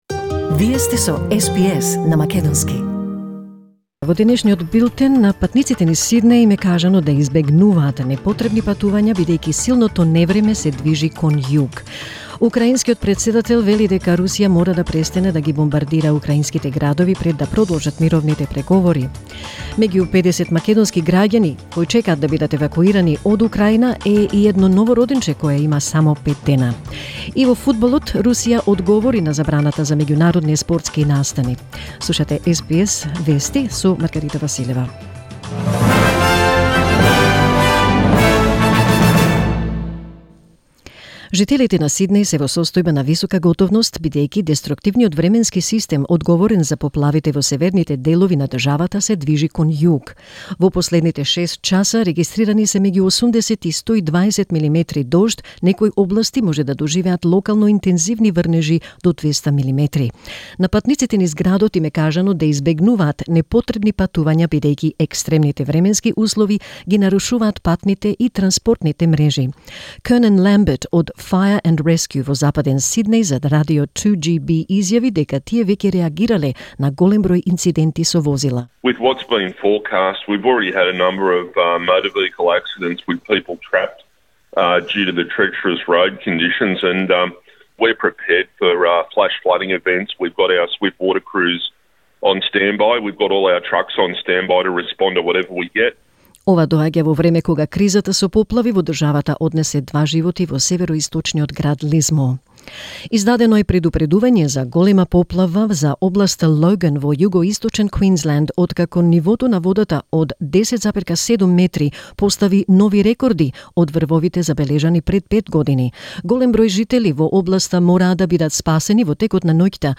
SBS News in Macedonian 2 March 2022